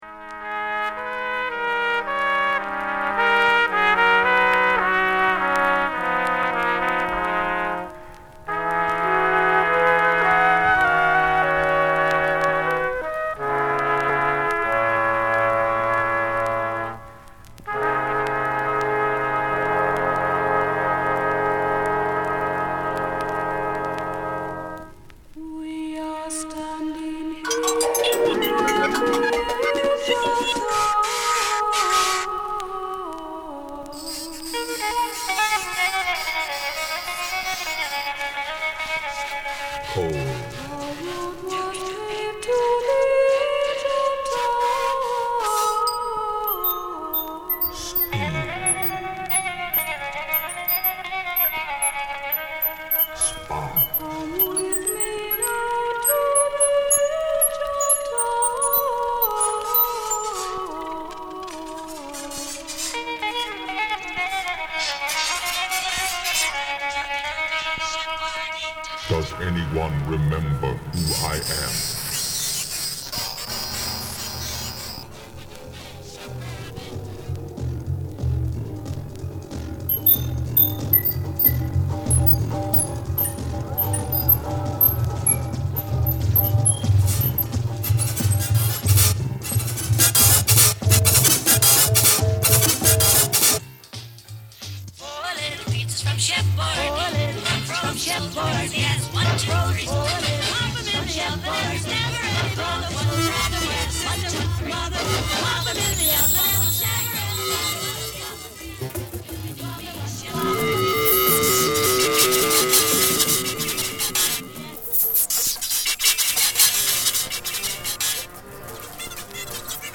deep dub techno and electro resolutions